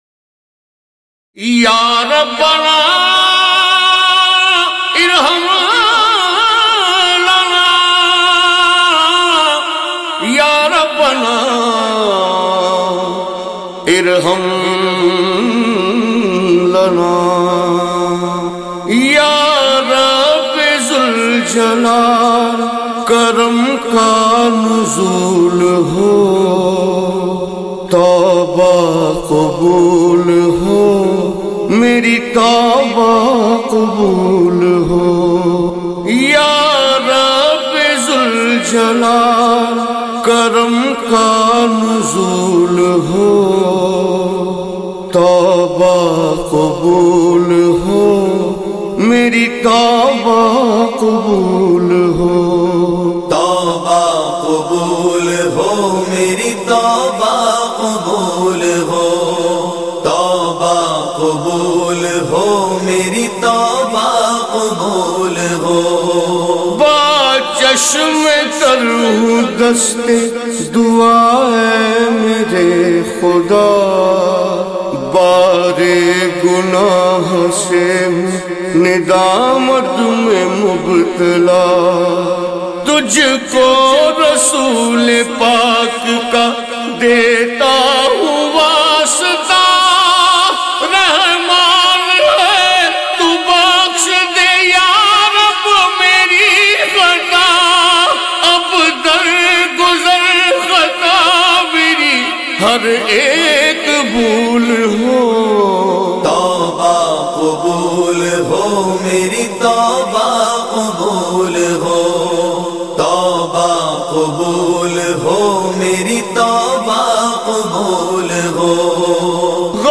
Download MP3 Worlds Largest Collection of Qawwali